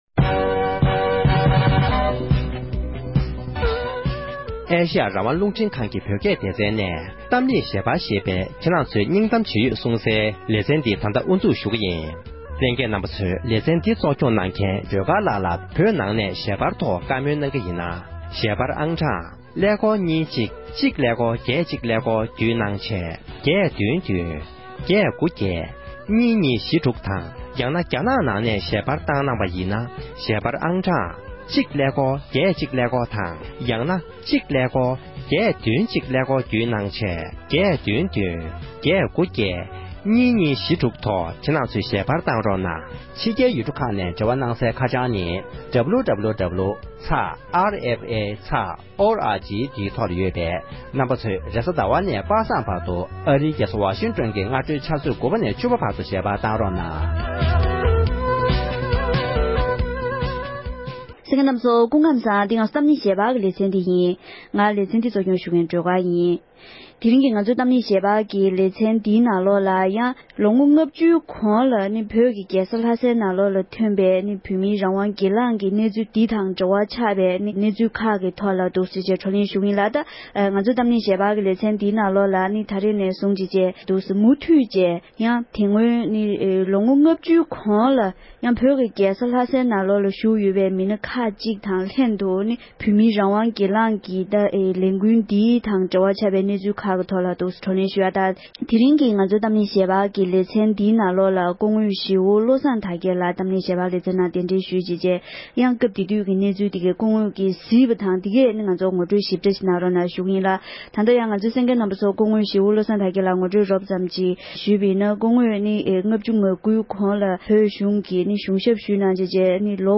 བོད་ནང་རང་དབང་སྒེར་ལངས་ཀྱི་ལས་འགུལ་ནང་དངོས་སུ་ཞུགས་མྱོང་མཁན་བཀའ་ཟུར་བློ་བཟང་དར་རྒྱས་ལགས་ཀྱི་ལྷན་གླེང་བ།